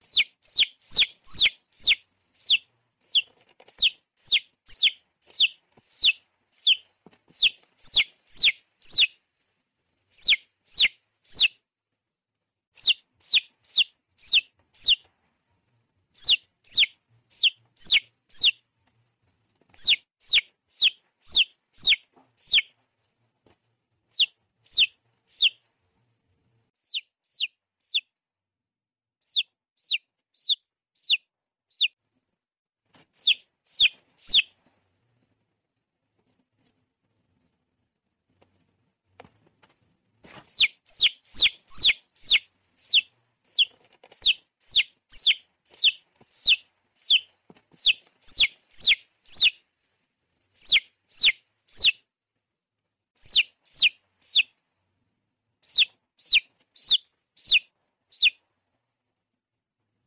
Tiếng Gà rừng con kêu mp3